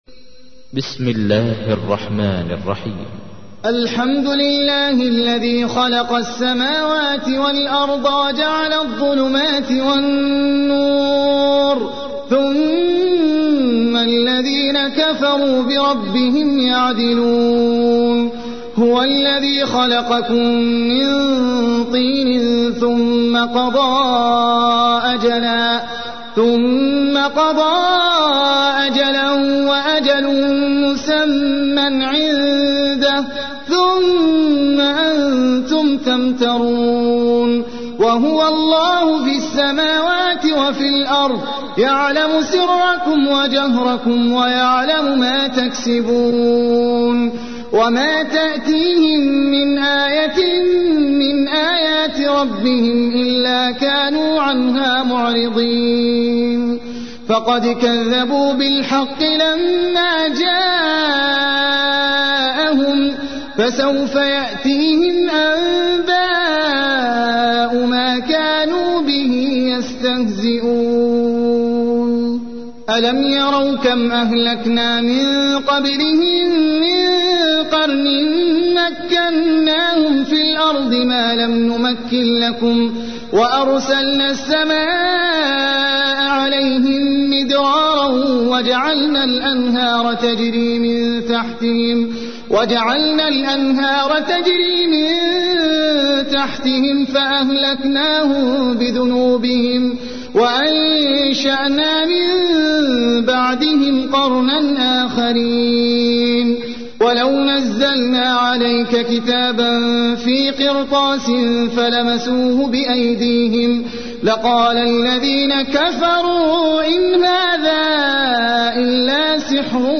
تحميل : 6. سورة الأنعام / القارئ احمد العجمي / القرآن الكريم / موقع يا حسين